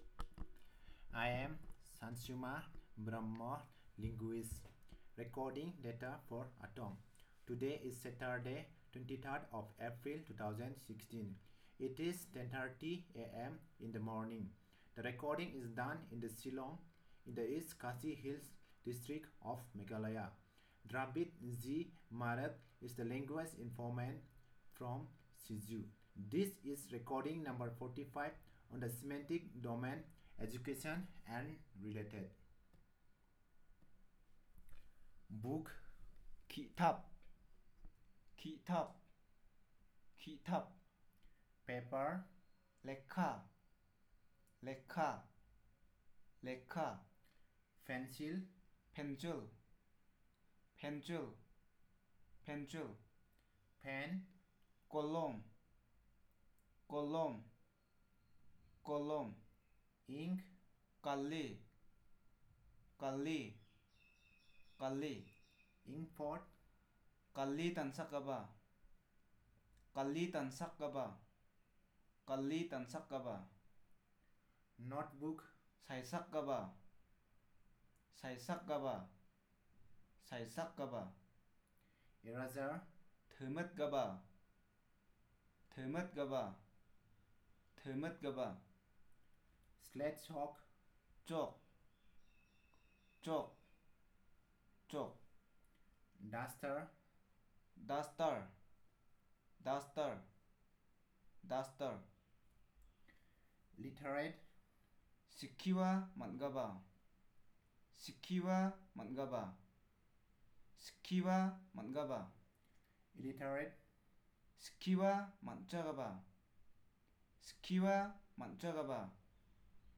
Elicitation of words about education and related